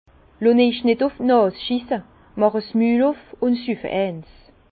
Bas Rhin
Ville Prononciation 67
Herrlisheim